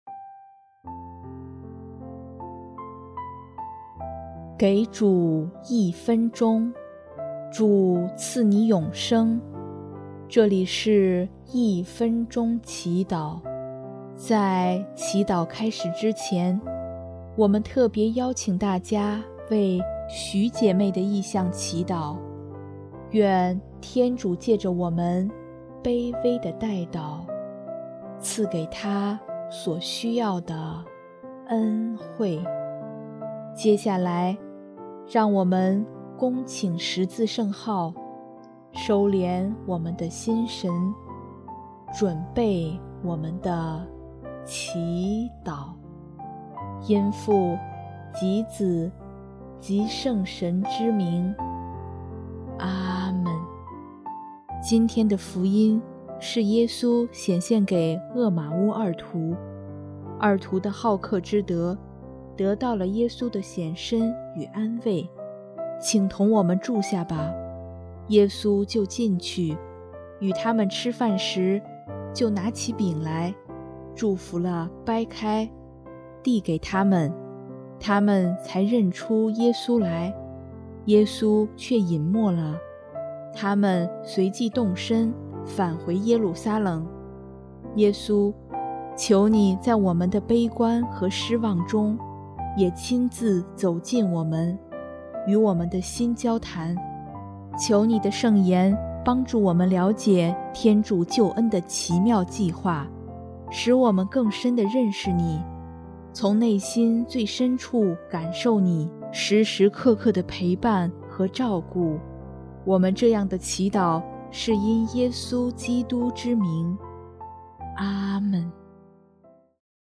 音乐：第四届华语圣歌大赛参赛歌曲《你是星光》